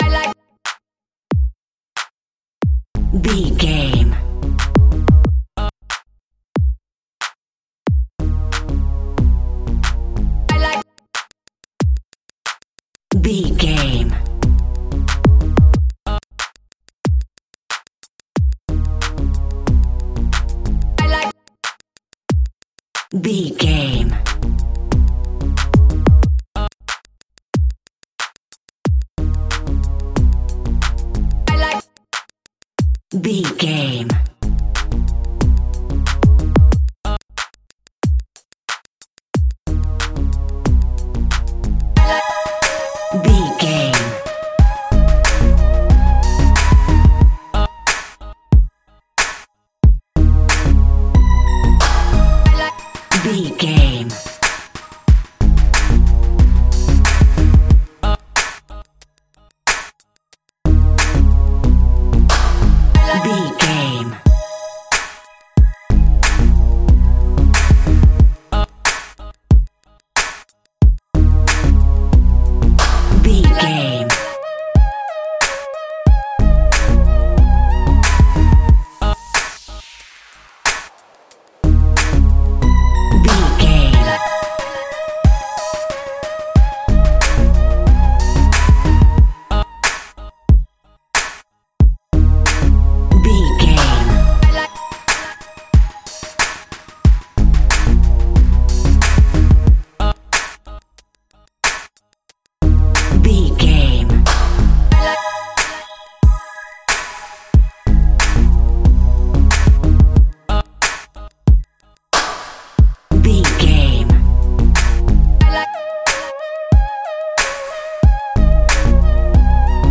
Aeolian/Minor
A♭
synthesiser